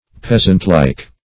Peasantlike \Peas"ant*like`\, a.